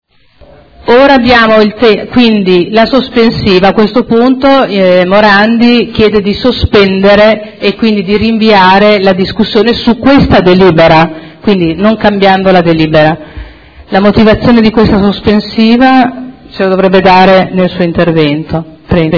Presidente — Sito Audio Consiglio Comunale
Seduta del 18/06/2012. Apre dibattito su sospensiva alla proposta di deliberazione chiesta dal Consigliere Morandi. Nuovo Mercato Ortofrutticolo all'Ingrosso